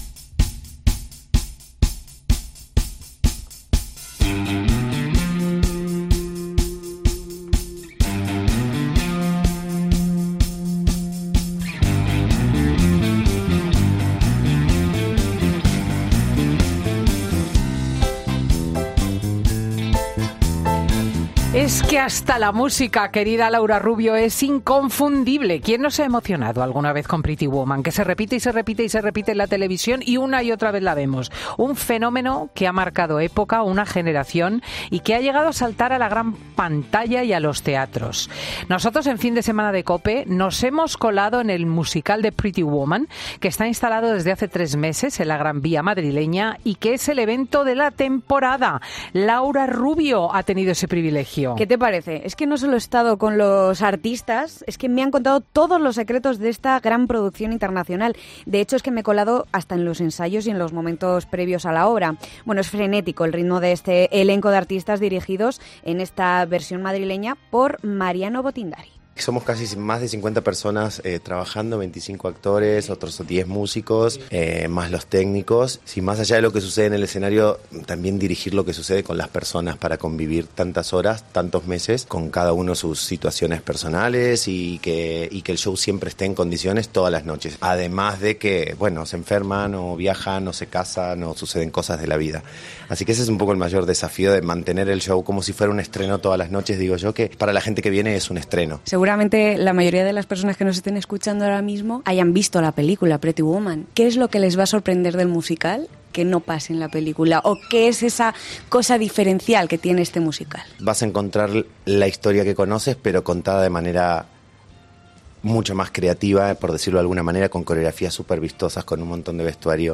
Fin de Semana de COPE, micrófono en mano, se ha colado en el musical de Pretty Woman que está instalado desde hace 3 meses en la Gran Vía madrileña y que promete ser el evento de la temporada.